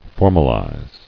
[for·mal·ize]